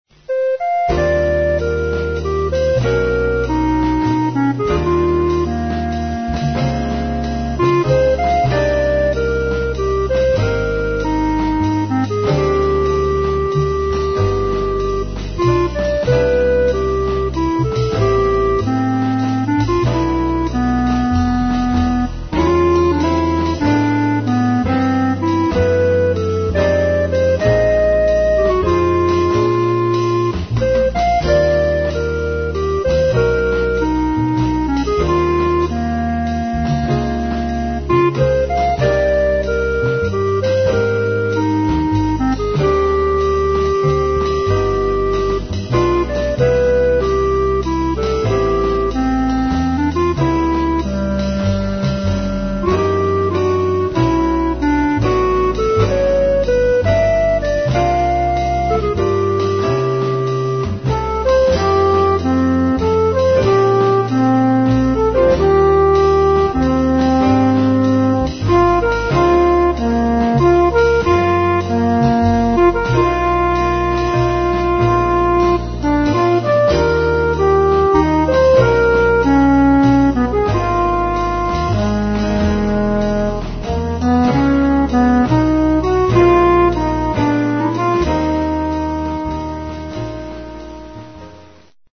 (alt, tpt unis)